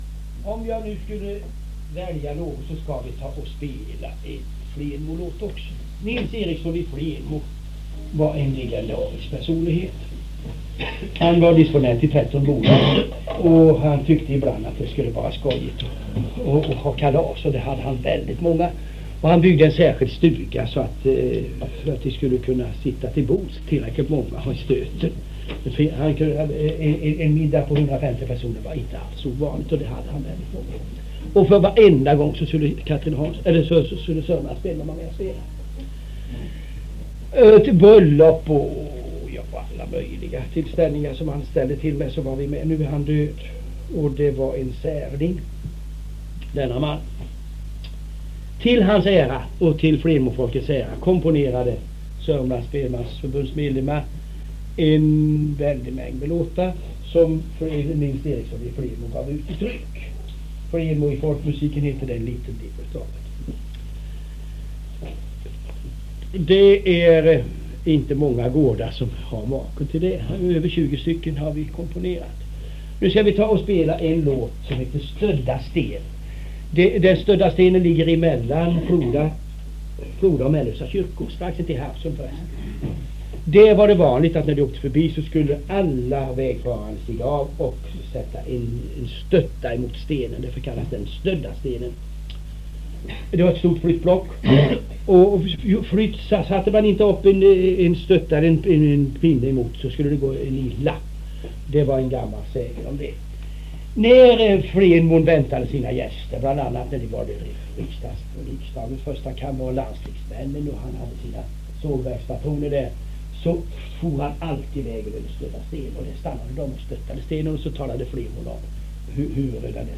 Han spelar polskan ”Stödda sten” komponerad av CG Axelsson, tillägnad Flenmo.
Utdrag från ett föredrag
ett kåseri i ord och toner betitlat Spelmän Jag Mött och Låtar Jag Hört.